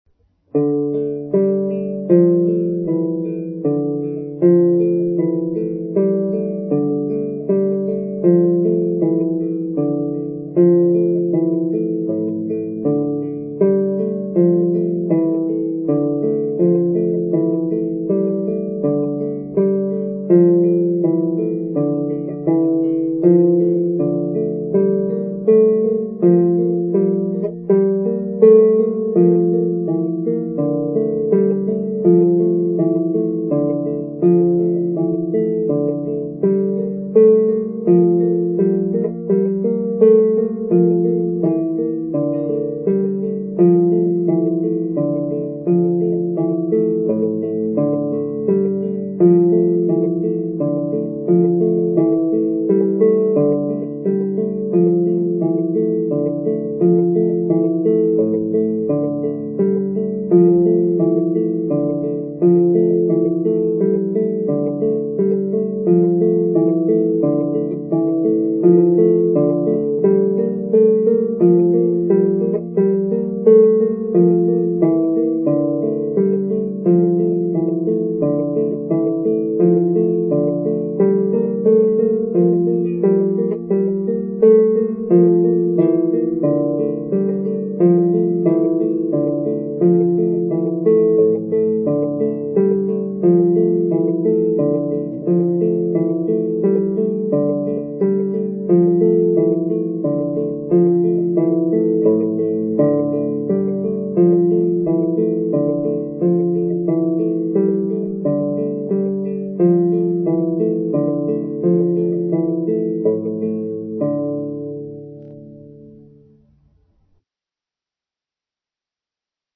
played on the Viking Lyre